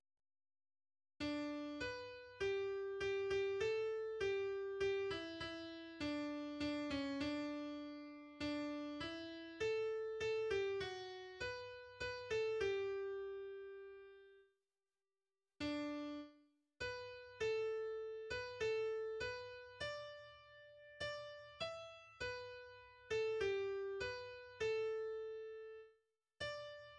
\version "2.12.3" \language "deutsch" \header { tagline = "" } \layout { indent = #0 } akkorde = \chordmode { \germanChords \set chordChanges = ##t } global = { \autoBeamOff \tempo 4 = 100 \time 3/4 \key g \major } melodie = \relative c' { \global r4 r d \repeat volta 2 { h'4 g( g8) g a4 g( g8) e e4 d( d8) cis d2 d4 e4 a( a8) g fis4 h( h8) a g2.